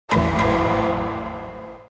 meme